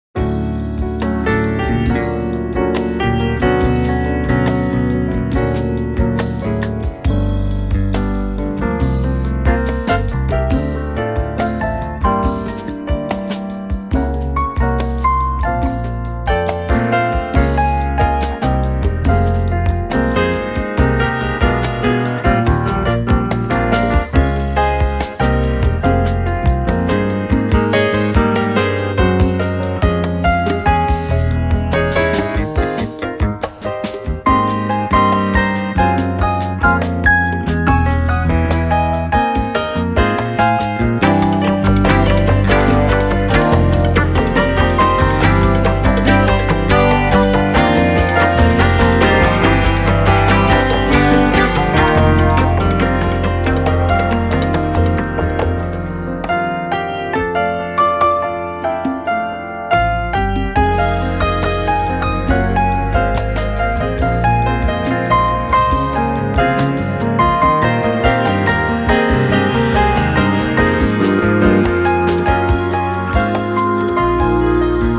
a peaceful and jazzy album